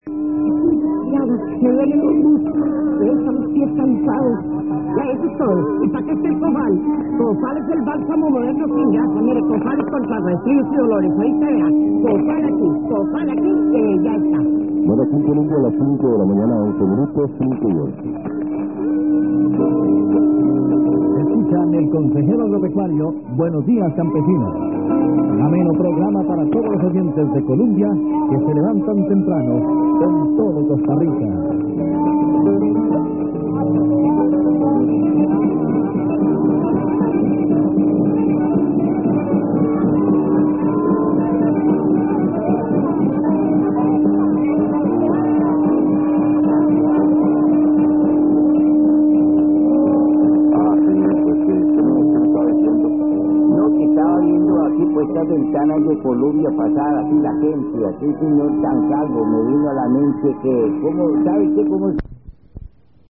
All recordings were made in Santa Barbara, Honduras (SB) using a Yaesu FRG-7 receiver.